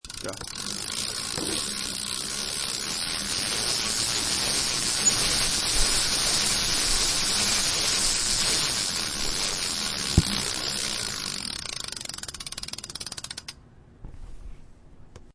Download E Bike sound effect for free.
E Bike